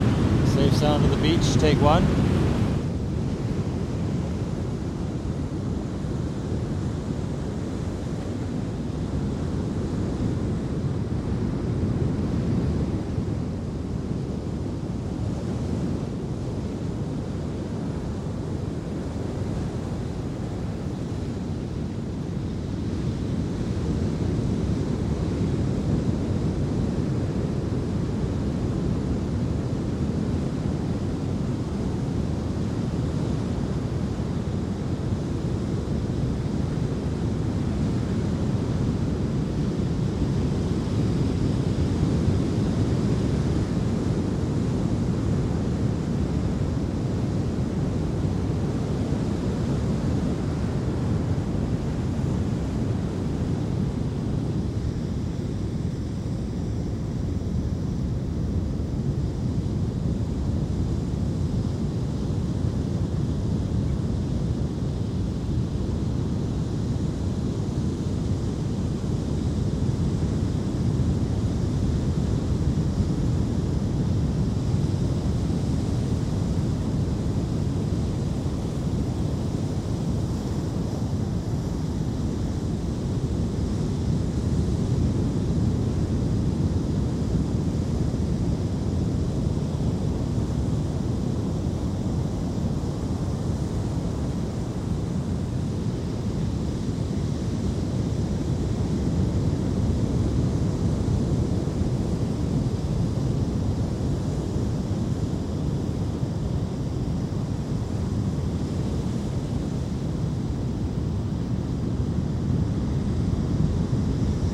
描述：在开普敦的桌湾海滩的现场录音与NTG3插入变焦H6声音
标签： 沙滩 海洋 打破波 海洋 海岸 海岸 海岸 海滨 冲浪
声道立体声